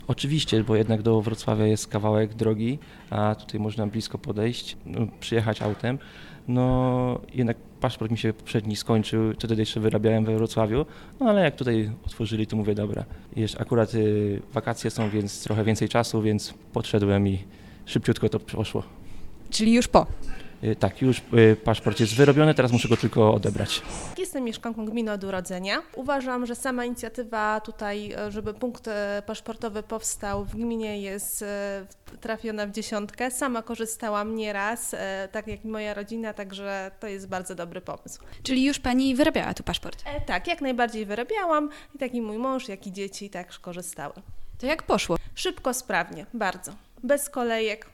Zapytaliśmy mieszkańców czekających w kolejce, czy punkt paszportowy w Kątach Wrocławskich jest potrzebny?